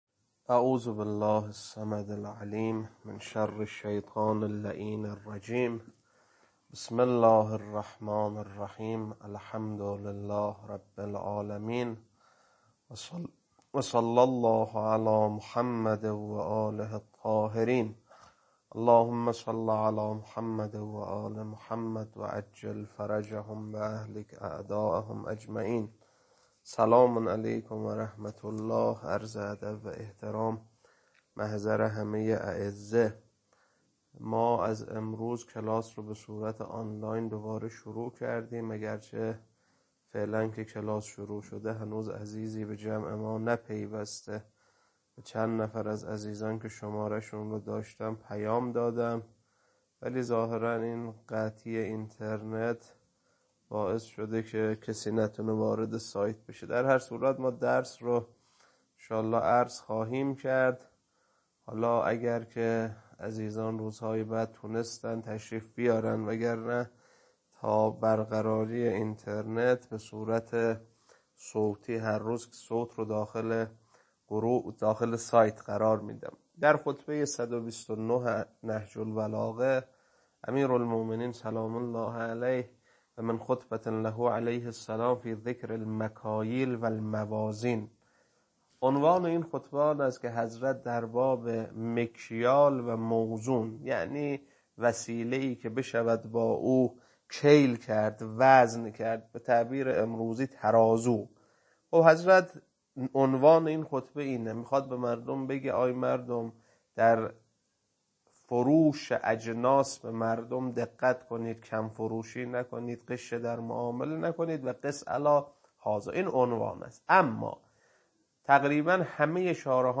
خطبه 129.mp3